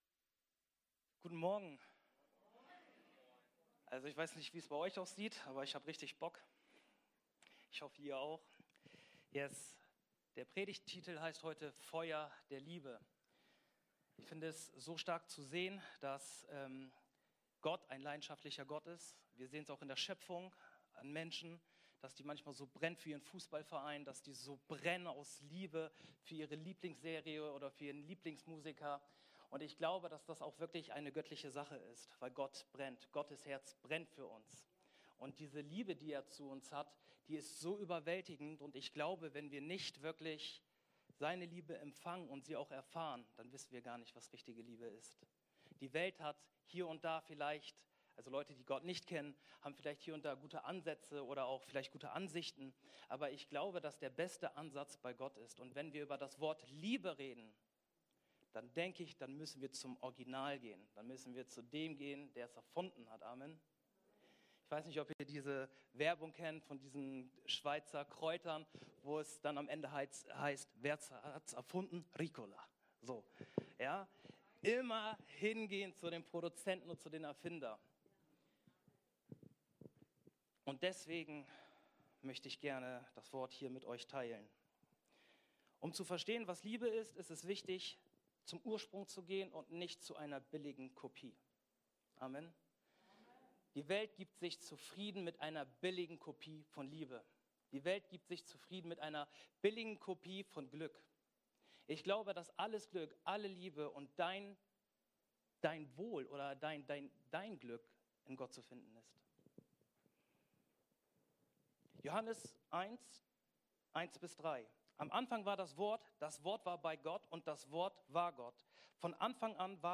Predigten (v1) – OASIS Kirche